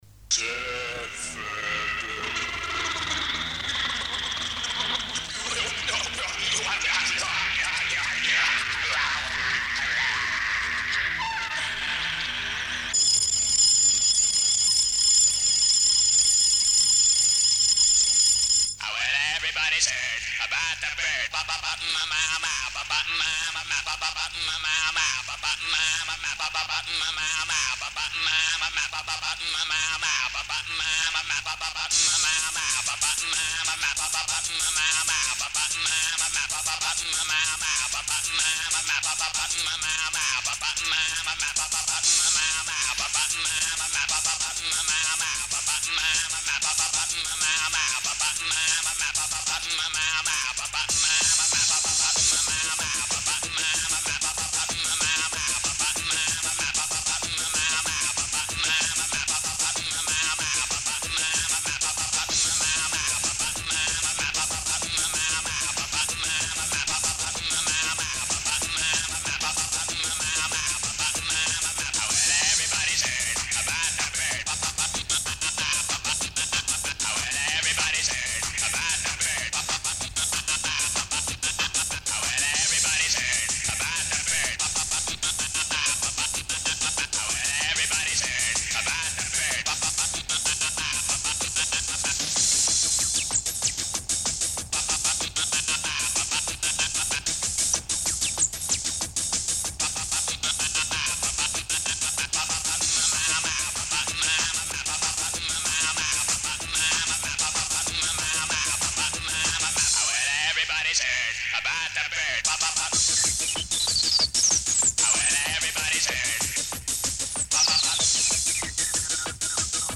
оцифровки скассет.